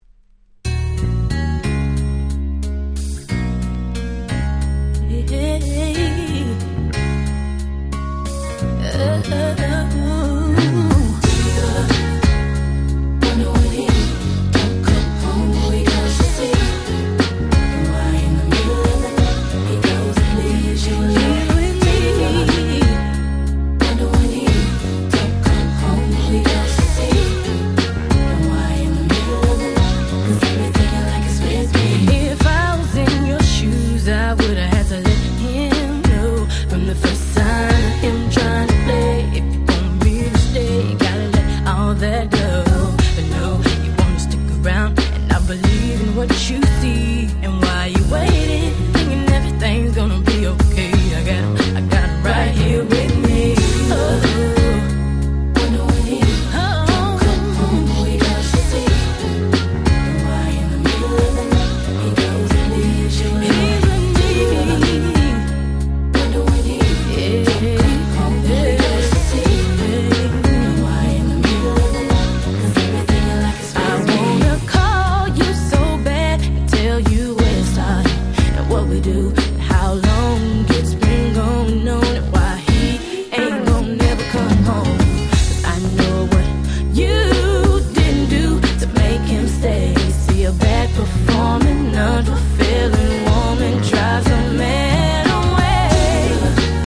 98' Smash Hit R&B !!